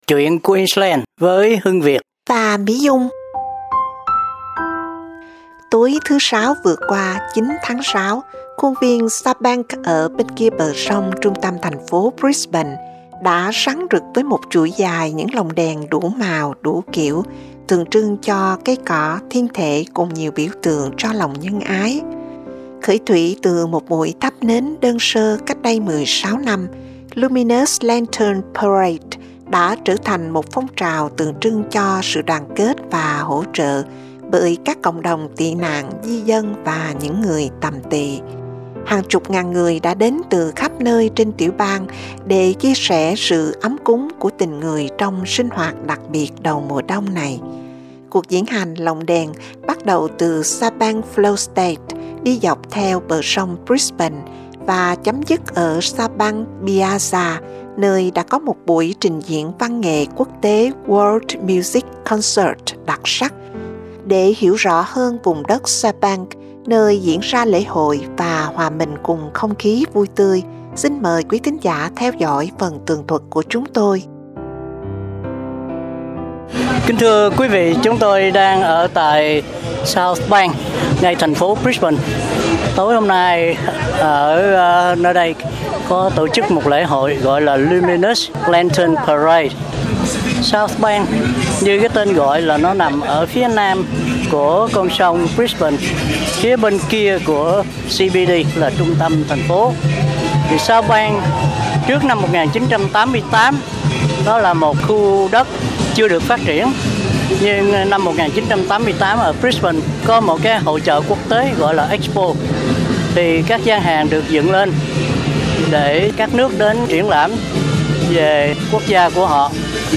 Luminous Lantern Parade in South Bank, Brisbane